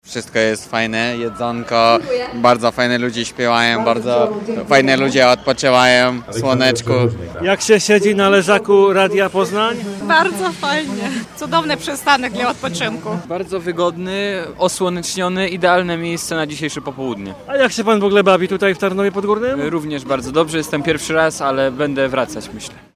Nasz błękitny namiot, stanowiący studio plenerowe, stanął w Parku 700-lecia, bardzo blisko sceny, dzięki czemu nasi radiowi słuchacze mogli poczuć gorącą atmosferę zabawy i festynu w Tarnowie.